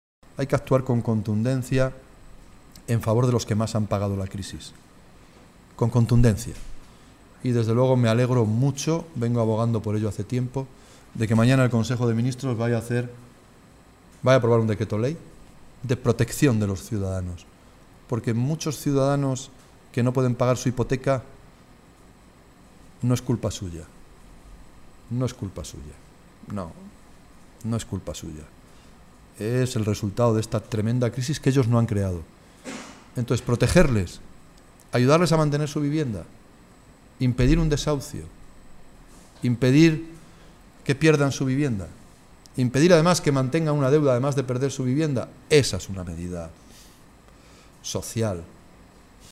Caldera que compareció en rueda de prensa antes de presentar en Toledo su libro “Tiempo para la Igualdad”, señaló que se han tenido que tomar medidas de ajuste muy importantes en nuestro país, “pero ahora que las cosas han empezado a serenarse, hay que actuar con contundencia a favor de los que más han pagado la crisis y me alegro mucho que mañana se apruebe esta medida porque la culpa de que muchos ciudadanos no puedan pagar su hipoteca no la tienen ellos sino la tremenda crisis que ellos no han provocado”.